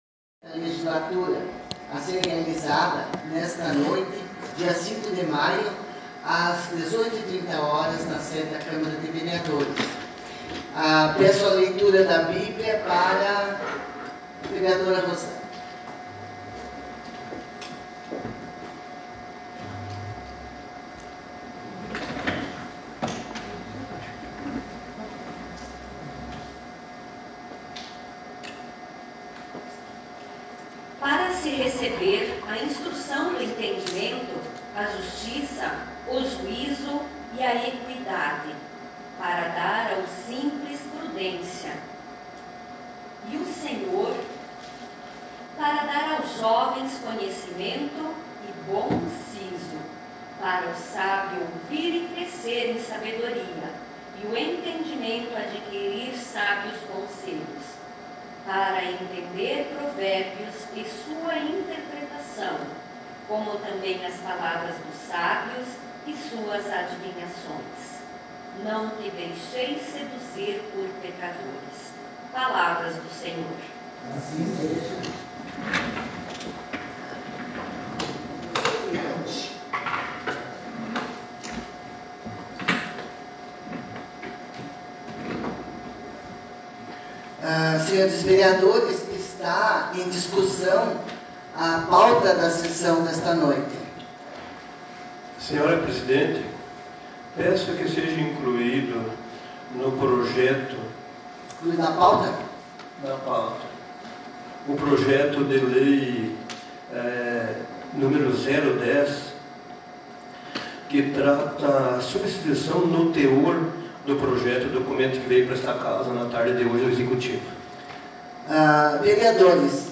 Sessão Ordinária 05 de maio de 2020